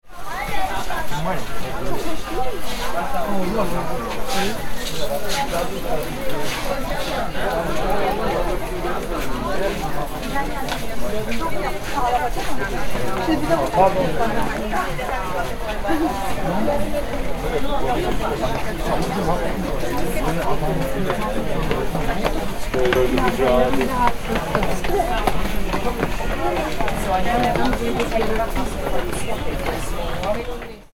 Istanbul Busy Shopping Street: Authentic Crowd Noise
Description: Istanbul busy shopping street crowd ambience. Authentic crowd ambience from a historic shopping district. People chatting, background street noise, and shop sounds.
Genres: Sound Effects
Istanbul-busy-shopping-street-crowd-ambience.mp3